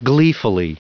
Prononciation du mot : gleefully
gleefully.wav